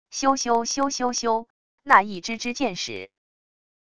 咻咻咻咻咻……那一支支箭矢wav音频